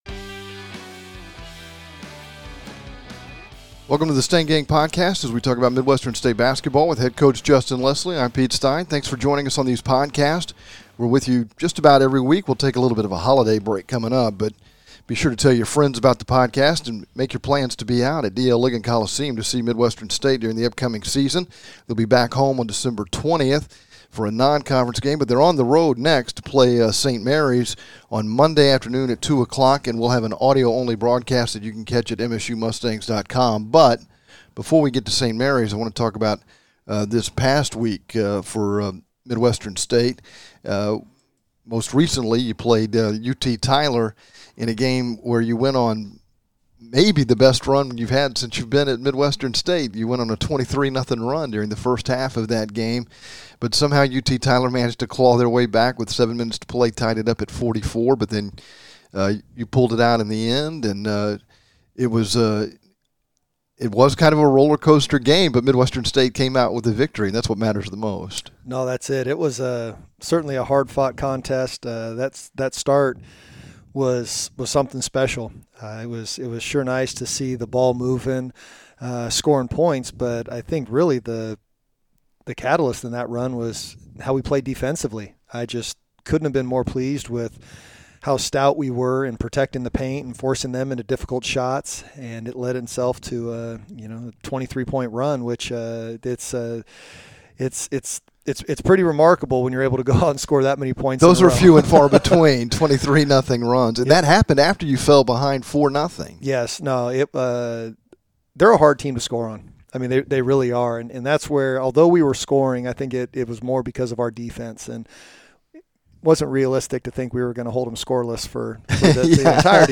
A candid conversation